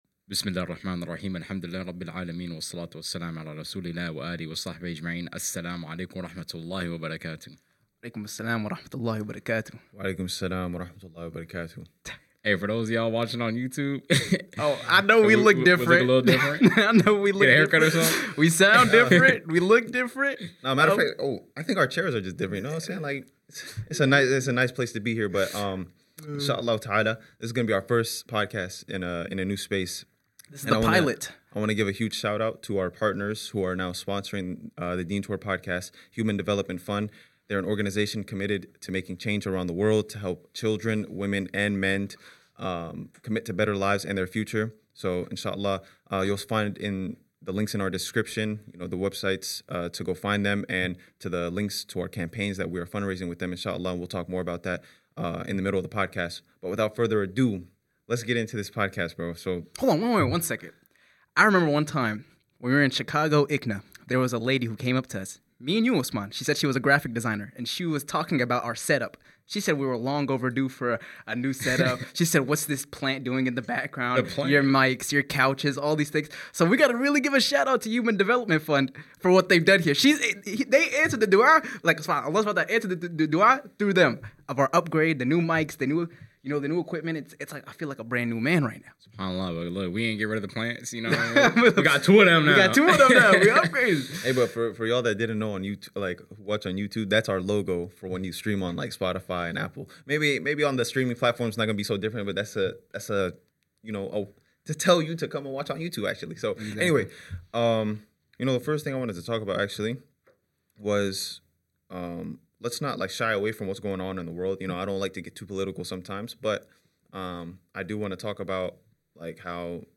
In this episode, we kick off a new direction for the DeenTour podcast! We talk about many topics in this pilot episode in our new studio while being hosted by our partners Human Development Fund.